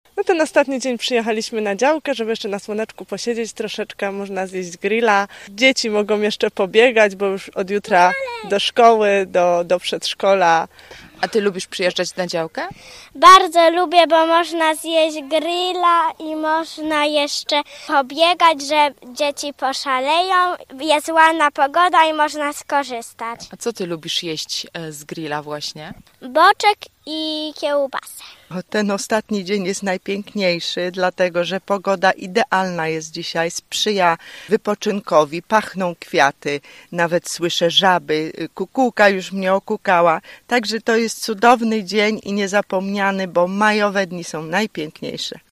– Dziś nikt nie chce siedzieć w domu – mówią nam mieszkańcy, których spotkaliśmy.